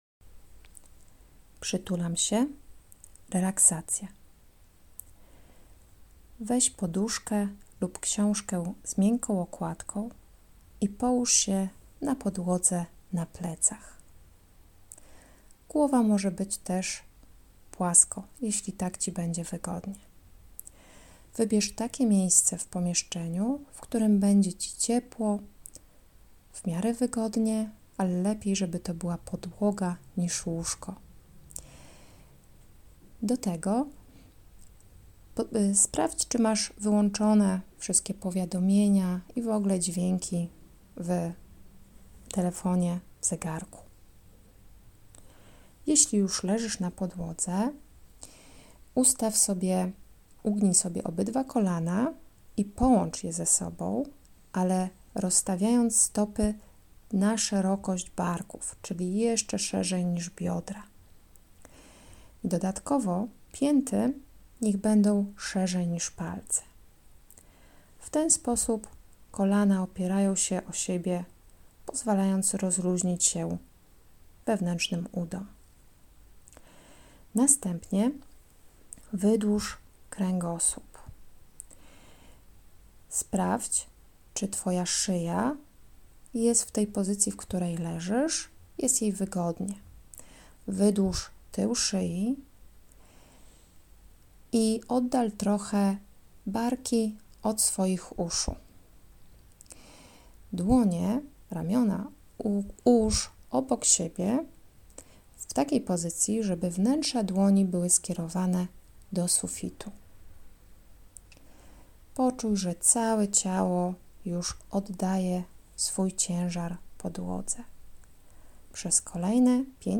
Nagranie mp3 w którym prowadzę relaksację.
relaksacja.mp3